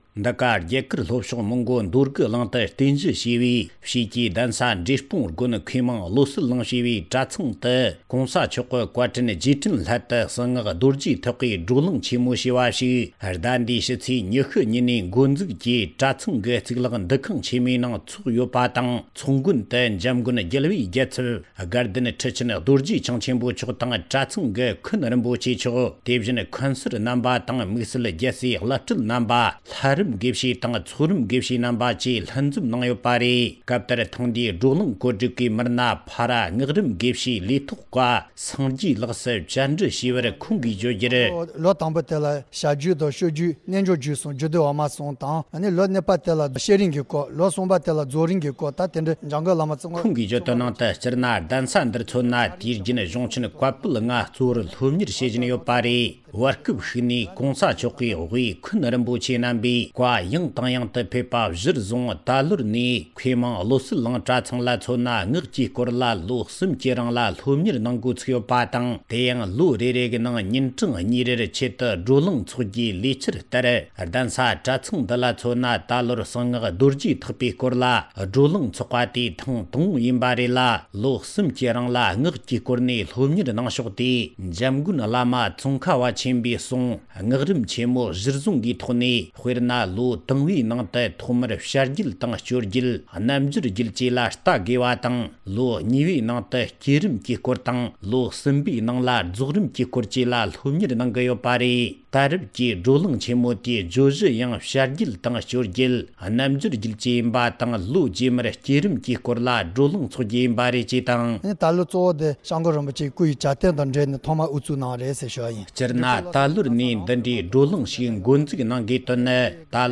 རྒྱ་གར་ལྷོ་ཕྱོགས་ནས་བཏང་བའི་གནས་ཚུལ་ཞིག